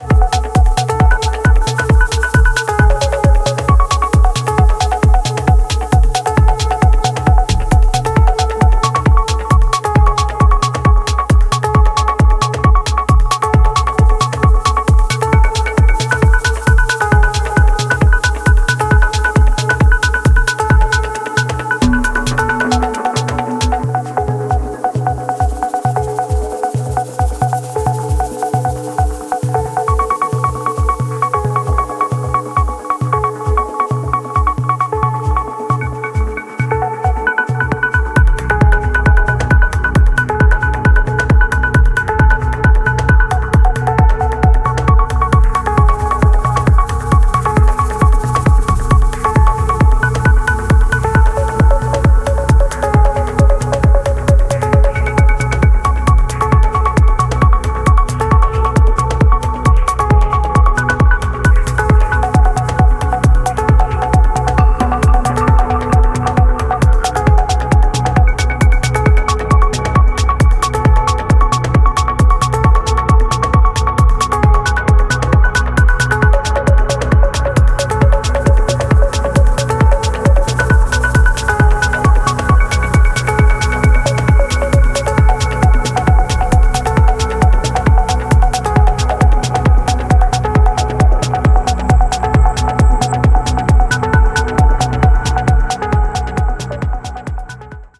研ぎ澄まされた音響センス、パワフルなグルーヴ、流石の完成度です。